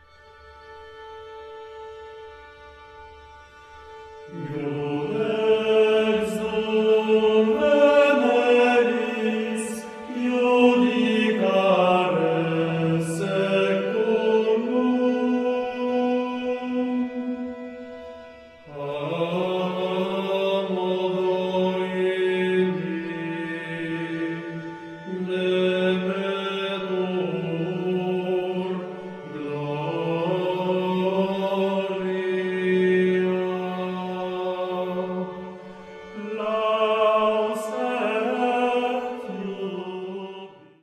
Współczesne prawykonania średniowiecznych utworów z Wrocławia, Cieszyna, Środy Śląskiej, Głogowa, Brzegu, Henrykowa, Żagania, kompozytorów anonimowych, Nicolausa Menczelliniego, hymny i sekwencja o św.
kontratenor, lutnia
fidel